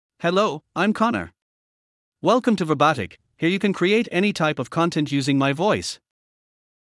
MaleEnglish (Ireland)
Connor — Male English AI voice
Connor is a male AI voice for English (Ireland).
Voice sample
Connor delivers clear pronunciation with authentic Ireland English intonation, making your content sound professionally produced.